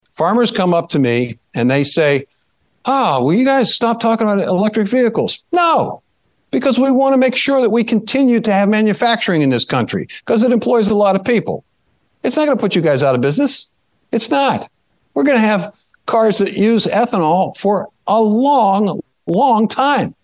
(NATIONAL ASSOCIATION OF FARM BROADCASTING)- US Agriculture Secretary Tom Vilsack told attendees of Growth Energy’s annual Hill Summit (Sept. 11-14, 2023), it’s a “make or break moment” for the biofuels industry when it comes to Sustainable Aviation Fuel.